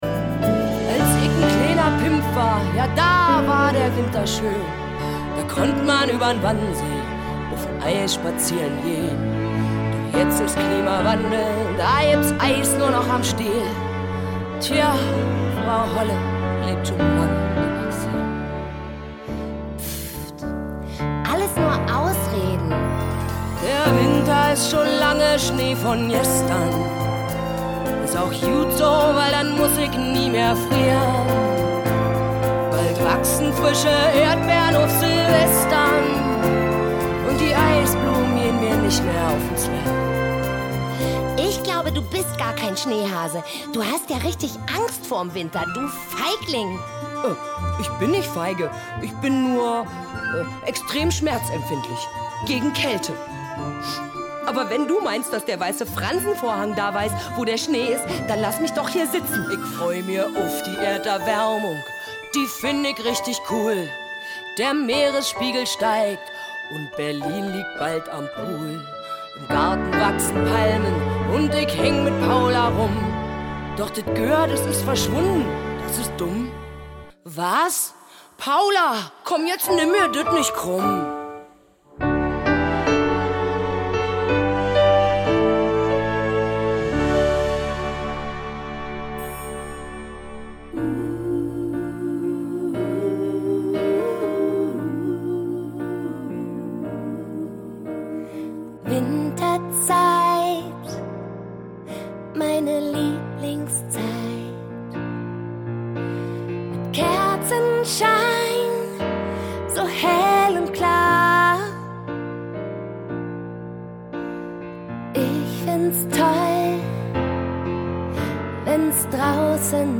Snippet 2 – Songs (deutschsprachig/mixed)